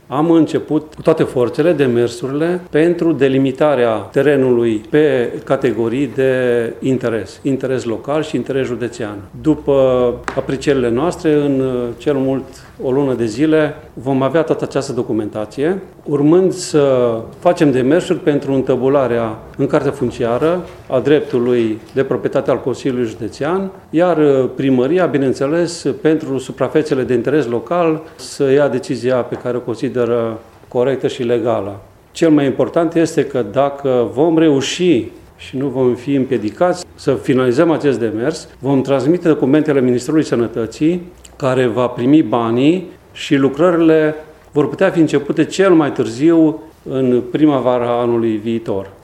Vicepreședintele Consiliului Județean Mureș, Alexandru Câmpeanu, a atras atenția astăzi, într-o conferință de presă, asupra unui posibil blocaj în realizarea centrului de mari arși la Târgu Mureș.